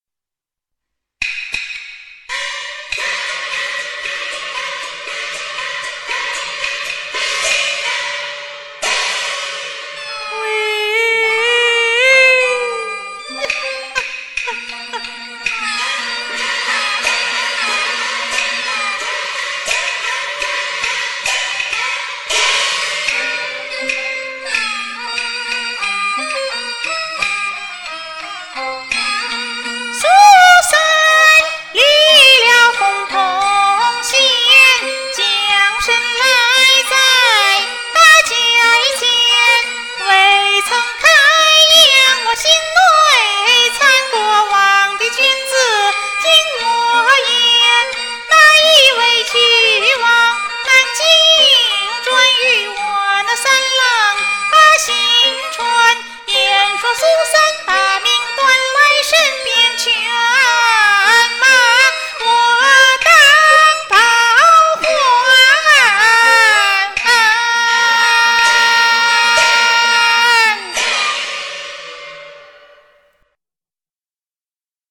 今早录了两录，第一录吐字断字太柔，第二录断字干脆一点，选择第二录贴上来：）
【西皮流水】